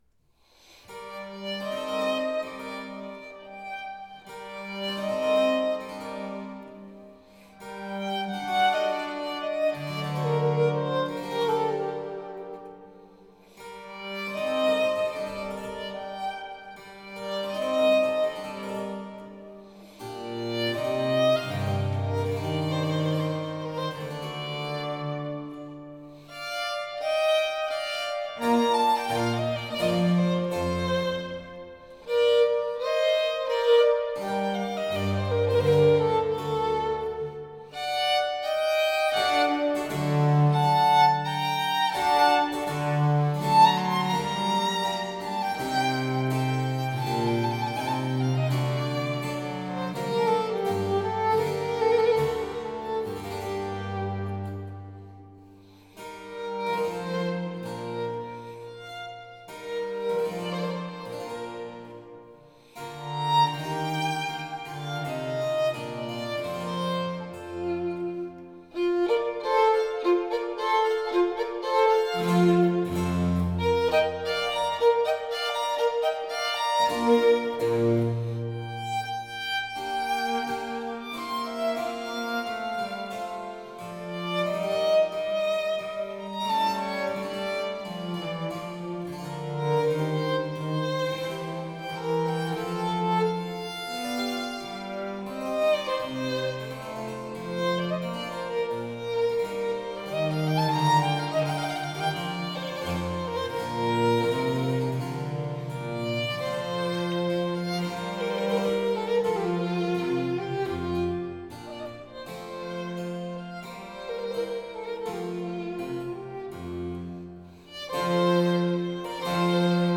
Sonate op. I & op. II per violino e basso
cello
cembalo